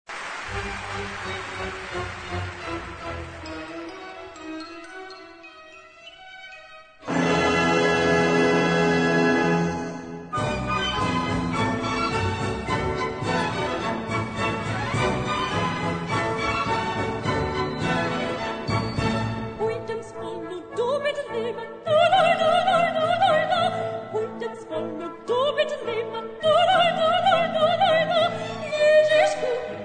music: Traditional
key: A-major